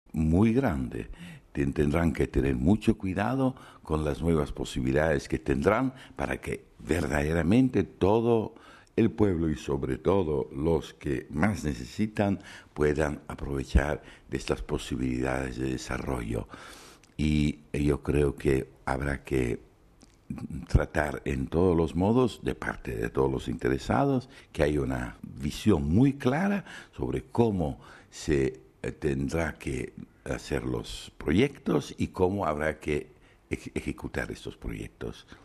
Entrevista al secretario del Pontificio Consejo Cor Unum sobre la decisión de la cancelación de la deuda a varios países en vías de desarrollo